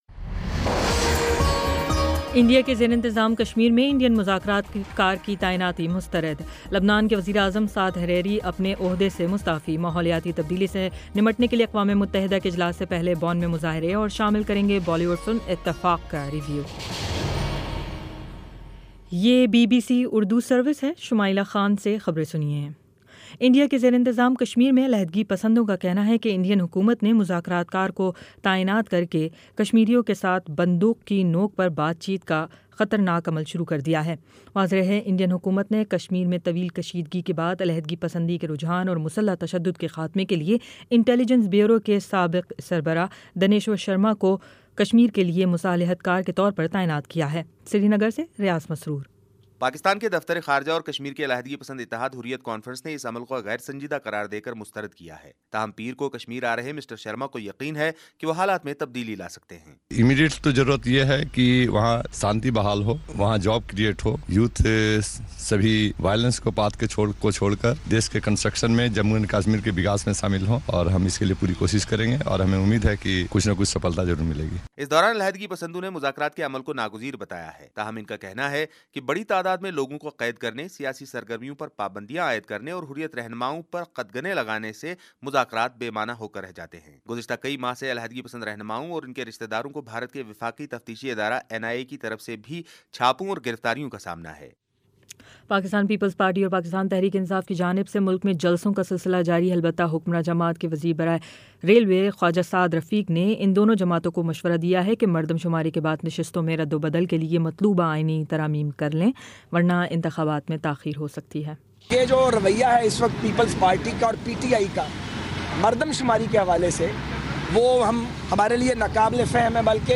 نومبر 04 : شام سات بجے کا نیوز بُلیٹن